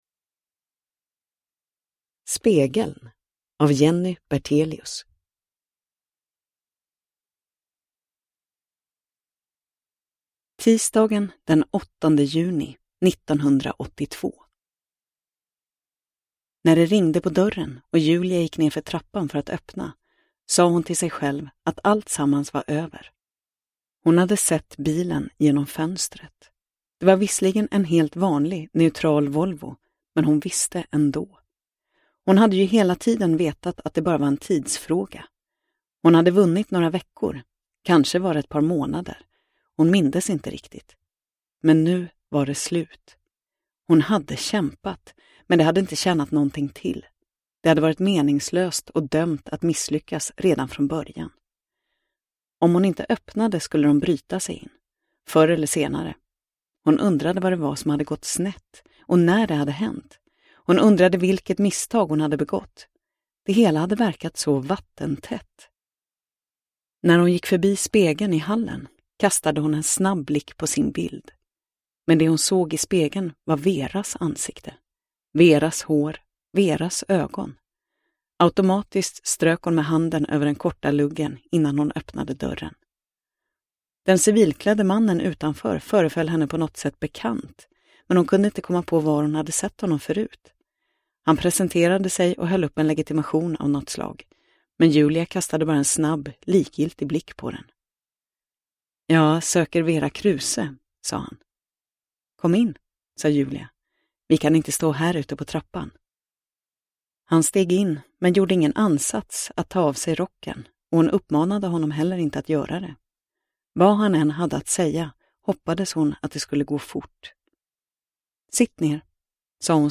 Spegeln – Ljudbok – Laddas ner